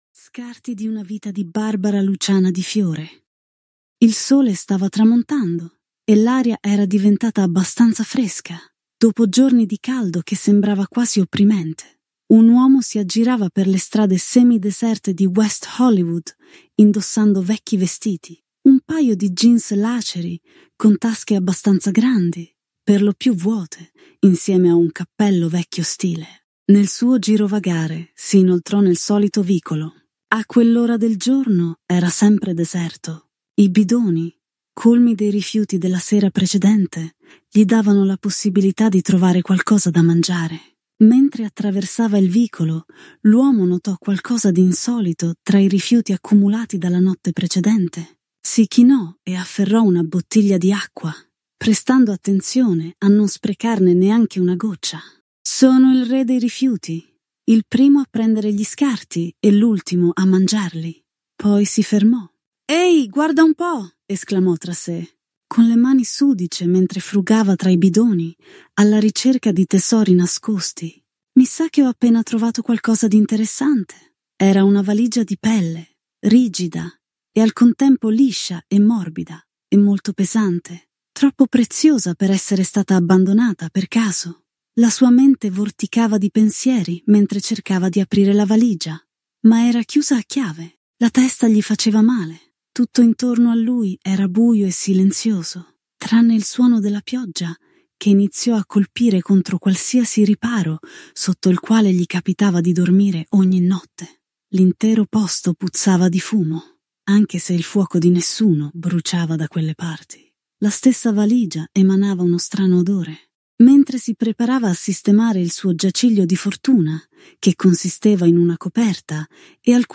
Scoprilo ora con l’audiolibro in omaggio.